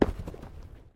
Звуки кувырка
Есть еще такой звук с кувырком человека